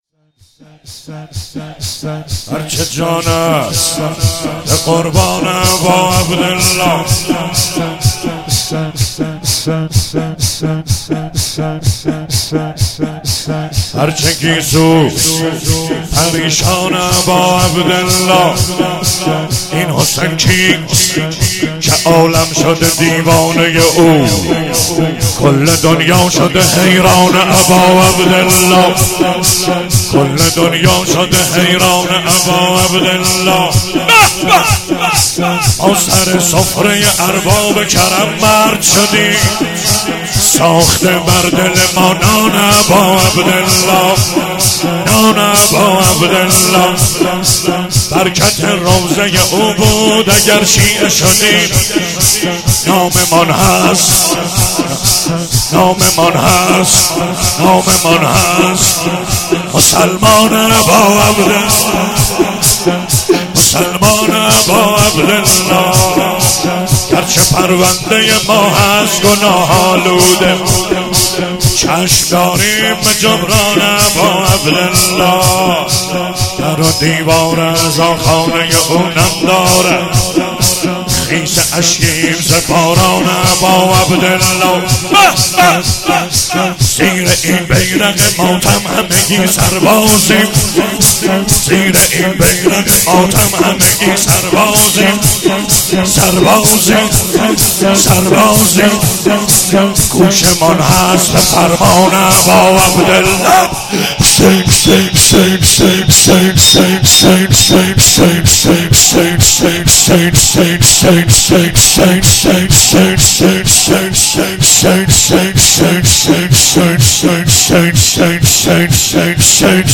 هفتگی 1تیر - شور- هر چه جان است به قربان اباعبدالله
مداحی